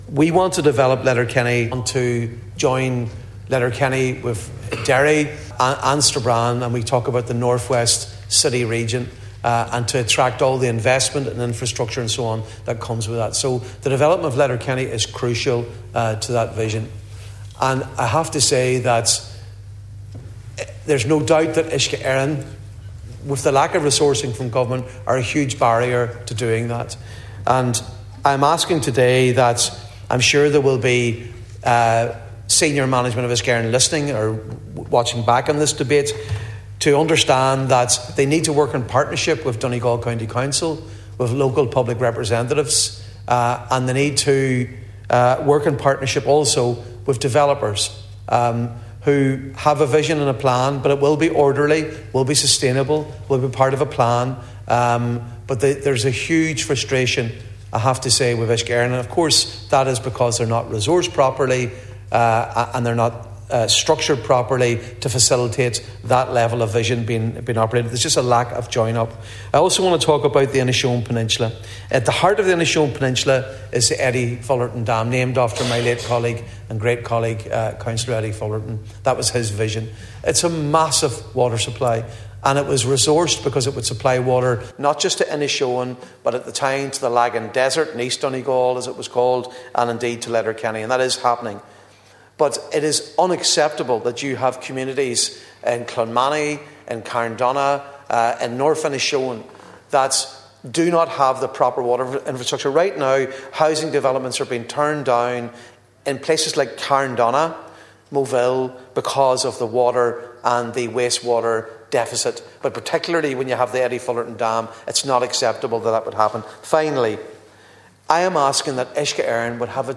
Speaking during a debate on Uisce Eireann, Deputy Padraig MacLochlainn told the Dail last evening it makes no sense that water is leaving Inishowen at a time that a lack of water is preventing development elsewhere on the peninsula.